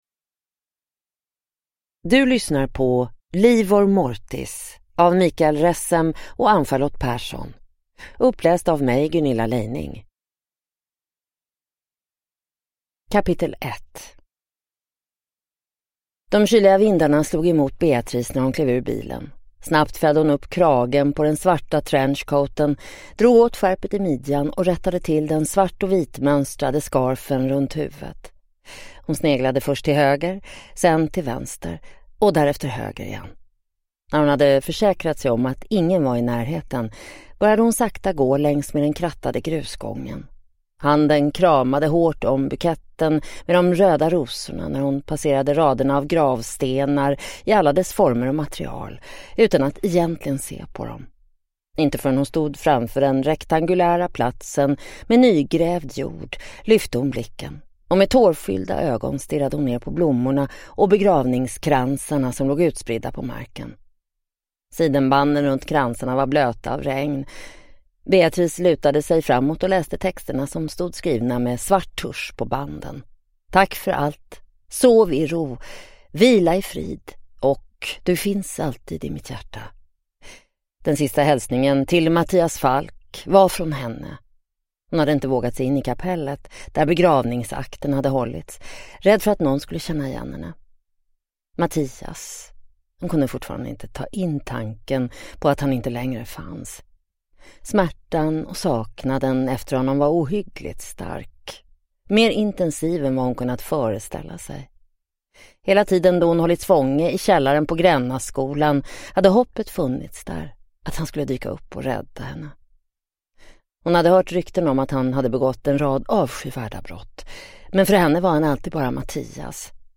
Livor mortis (ljudbok) av Mikael Ressem